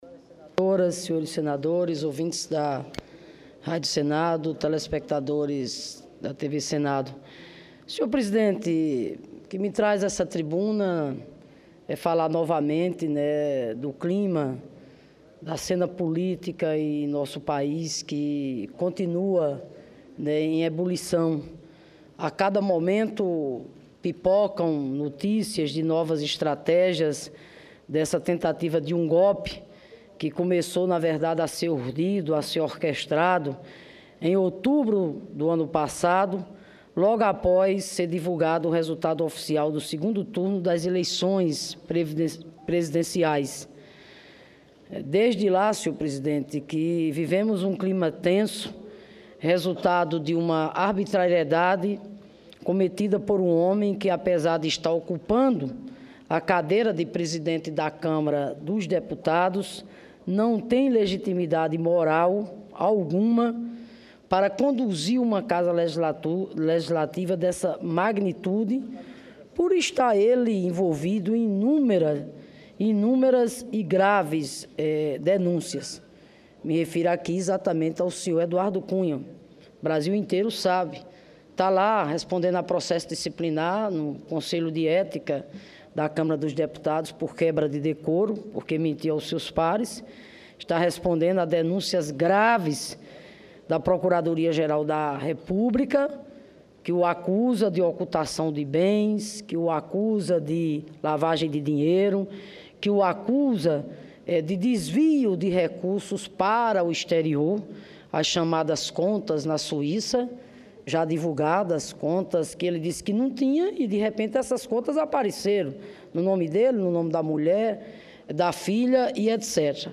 Plenário
Discursos